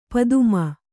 ♪ paduma